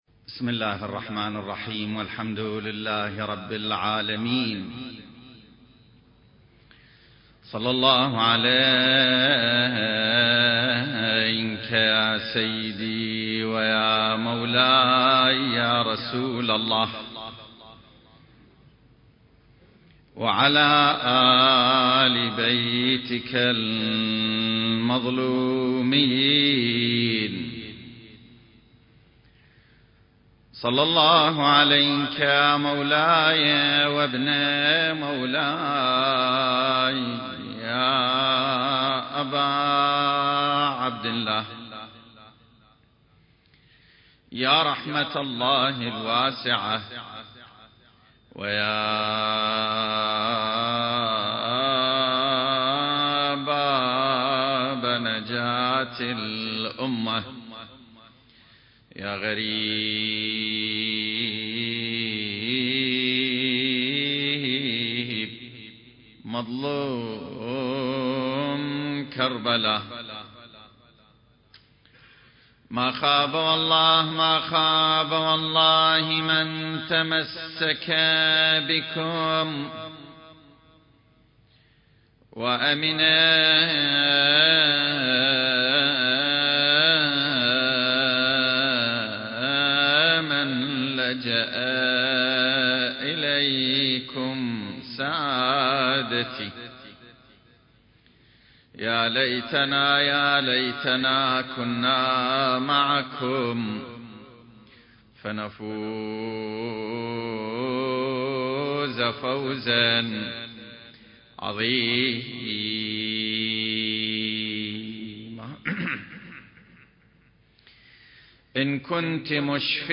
سلسلة محاضرات